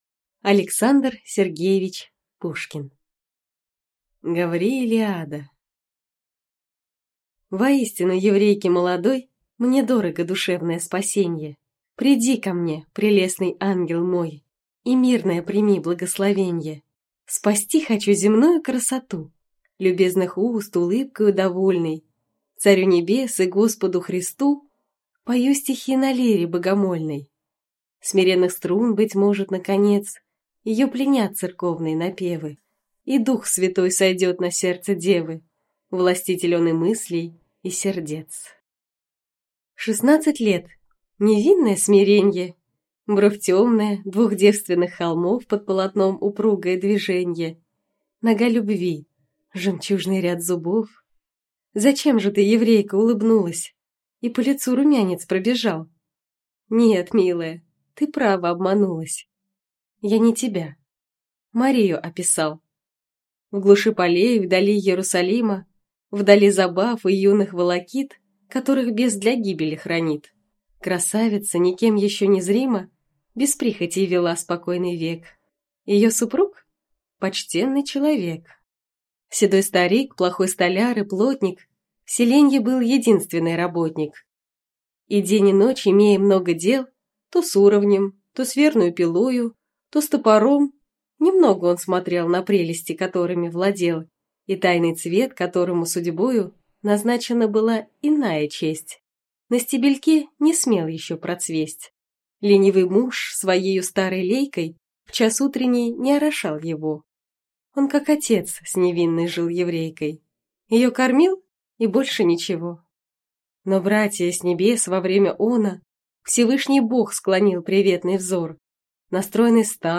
Аудиокнига Гавриилиада | Библиотека аудиокниг